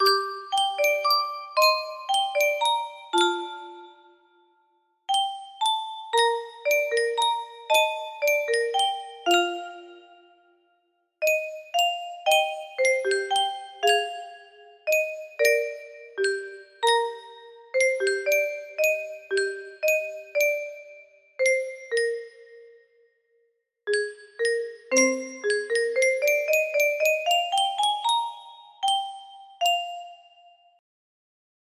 Guide track for the Choir, with a theme of ascendence.